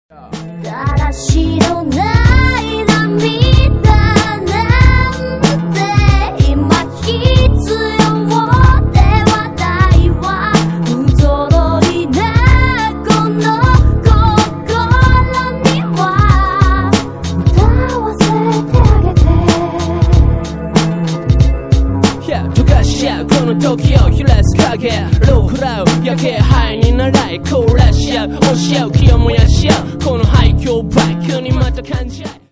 the Eminem- and Dido-style vocal track